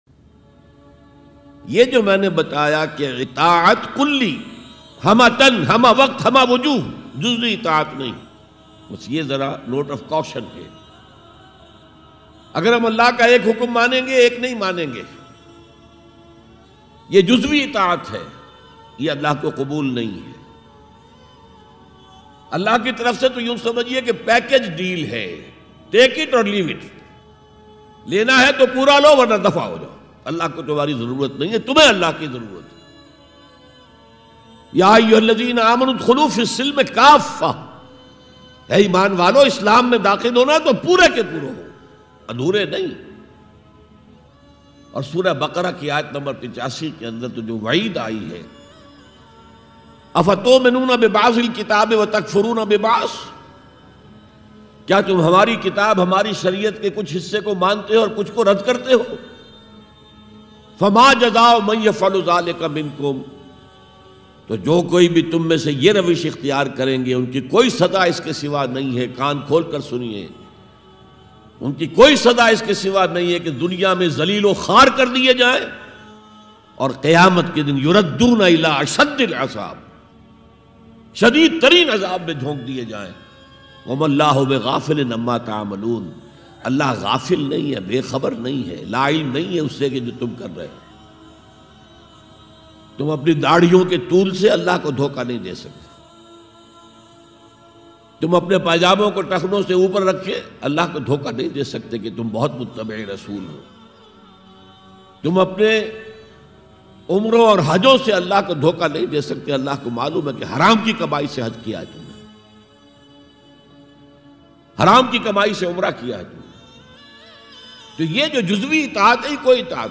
Dr Israr Ahmed R.A a renowned Islamic scholar. His lectures and sermons are listen globally on Quran, Politico Socio Economic System of Islam, Global Affairs and End Times.